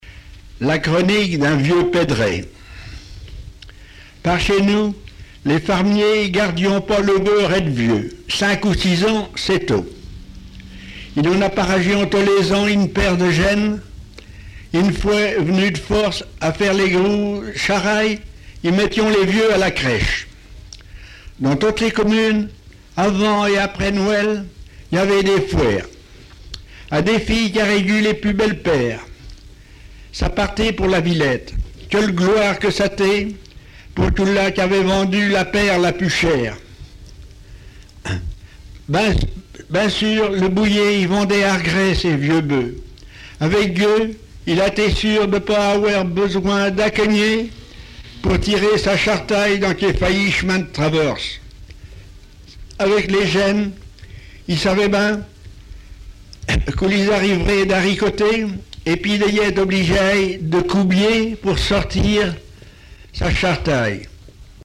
lecteur pays de Retz inconnu
Genre récit
textes en patois et explications sur la prononciation